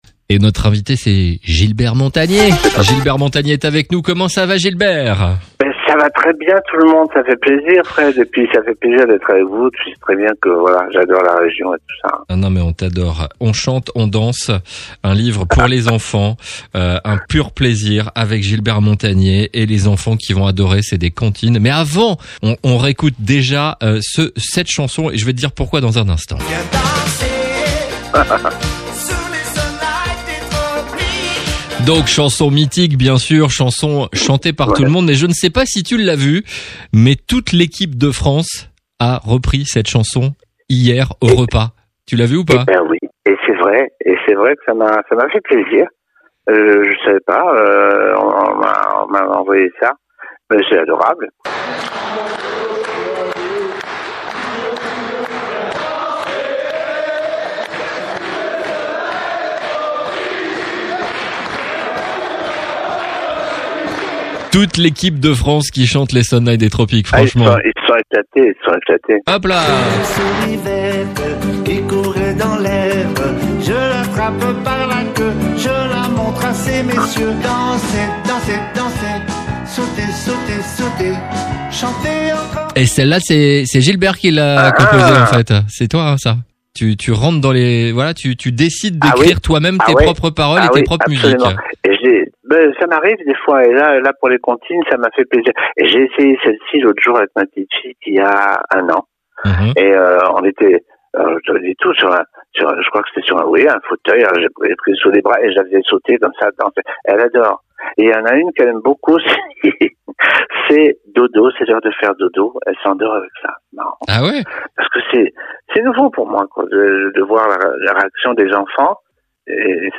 3. Les interviews exclusifs de RCB Radio